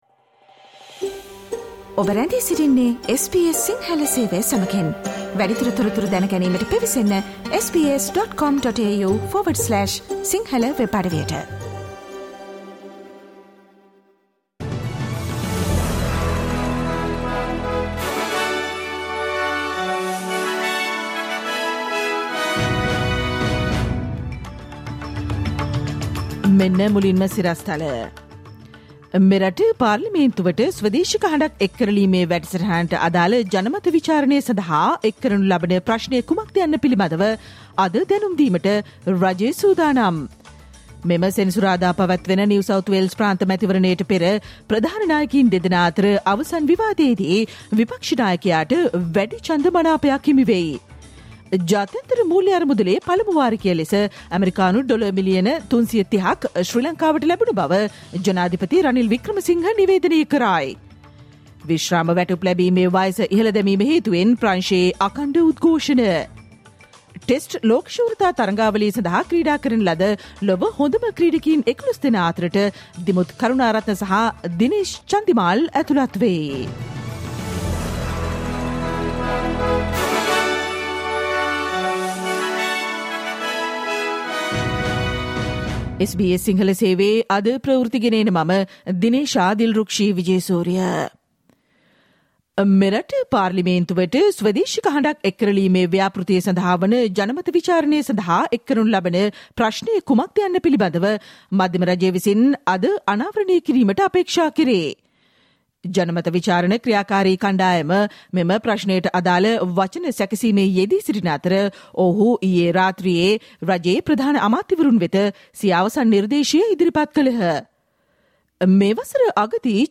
Australian news in Sinhala language on Thursday 23 March 2023
Listen to the latest news from Australia, Sri Lanka, and across the globe, and the latest news from the sports world on the SBS Sinhala radio news bulletin on Monday, Tuesday, Thursday, and Friday at 11 am.